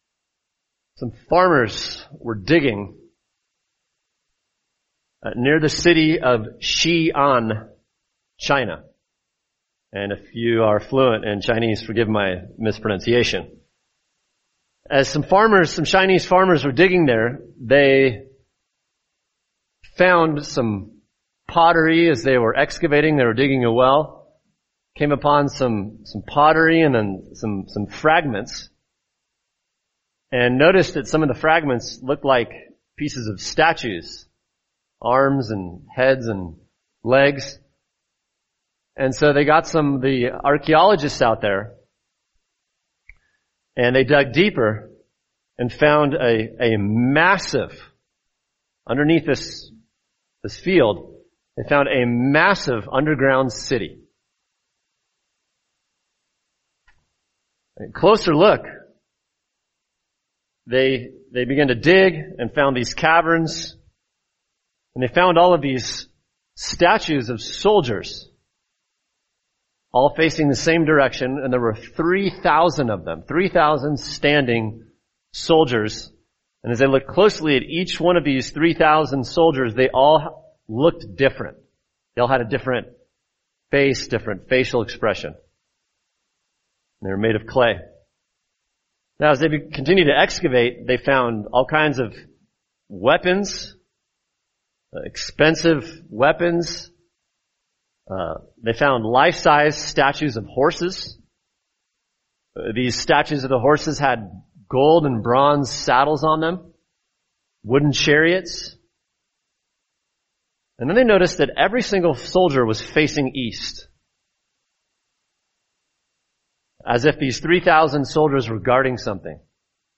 [sermon] Matthew 28:8-15 The Truth Telling Lie: The Resurrection of Christ, Part 2 | Cornerstone Church - Jackson Hole